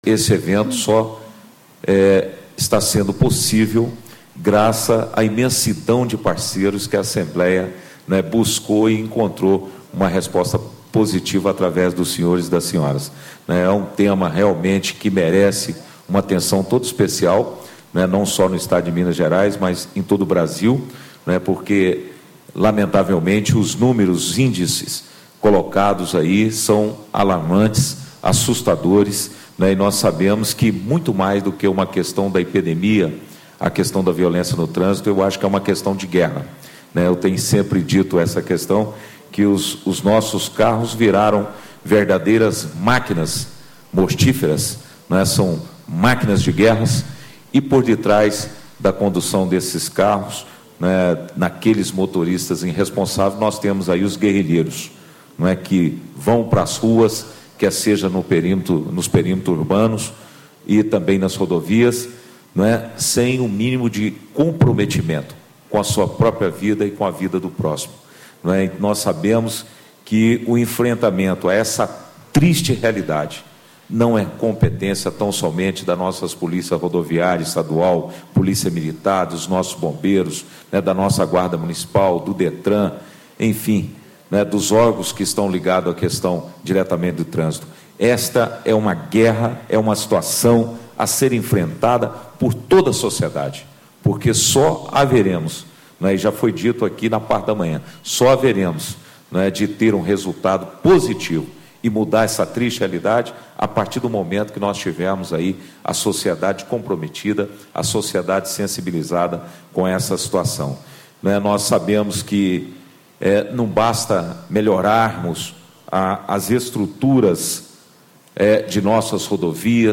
Deputado Bosco, PTdoB, Presidente da Comissão de Educação
Discursos e Palestras